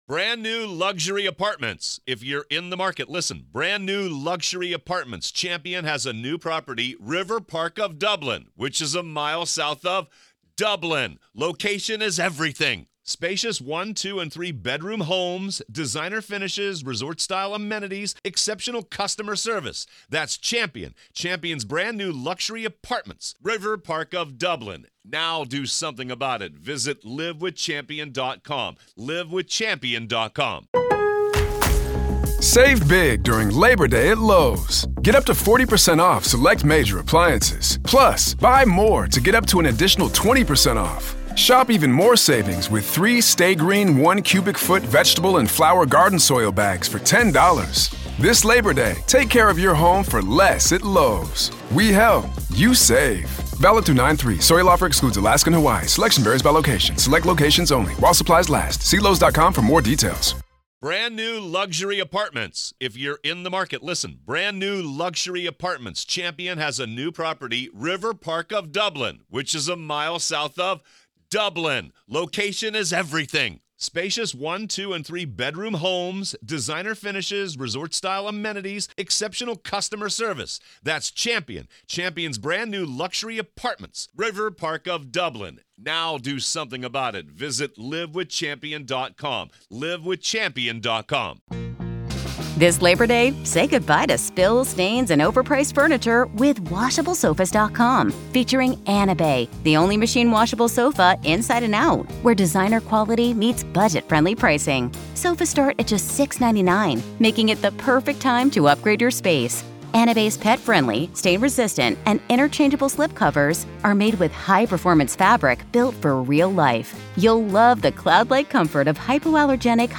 Daily True Crime News & Interviews